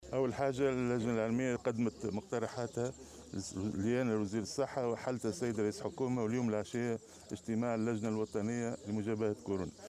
قال وزير الصحة، فوزي المهدي، في تصريح اليوم لمراسل "الجوهرة أف أم"، على هامش زيارته إلى ولاية القيروان، إن اللجنة الوطنية لمجابهة فيروس كورونا ستجتمع عشية اليوم الخميس، وذلك للنظر في المقترحات التي تقدّمت بها اللجنة العلمية.